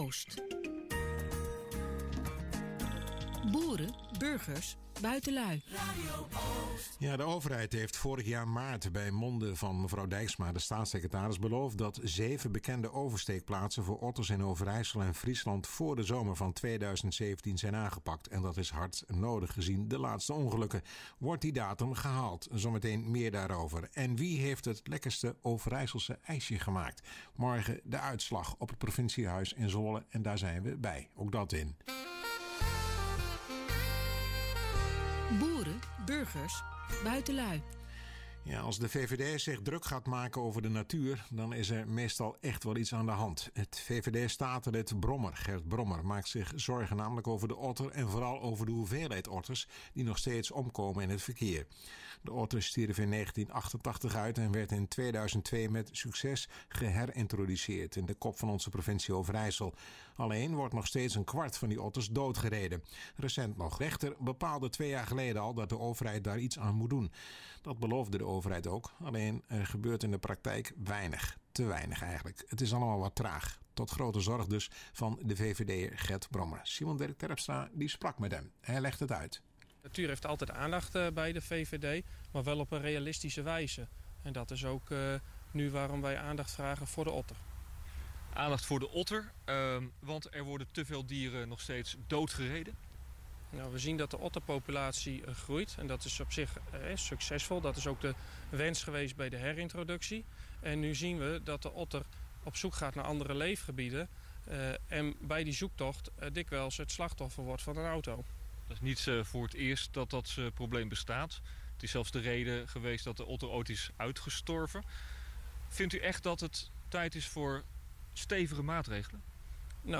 Beluister hieronder het interview dat RTV Oost met Gert Brommer had.